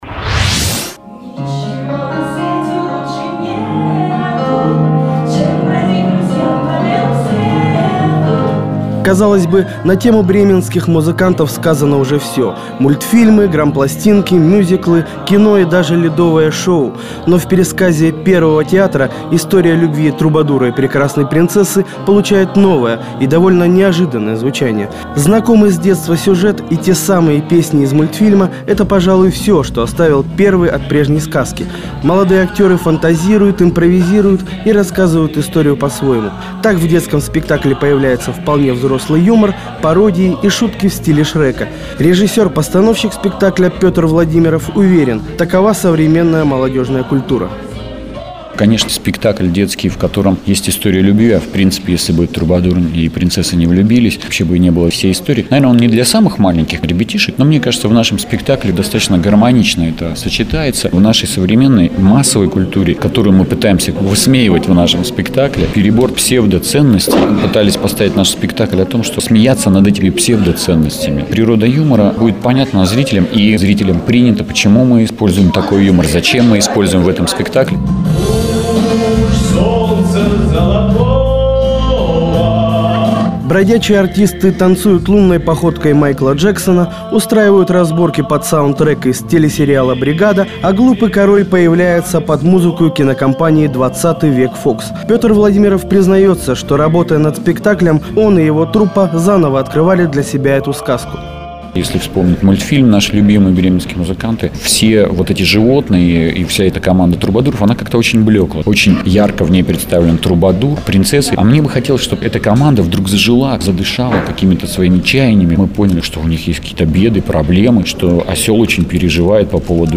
Спектакль "Первого театра" "Бременские музыканты". 16 ноября 2009 г.
Мои репортажи, вышедшие в эфир Радио "Городская волна"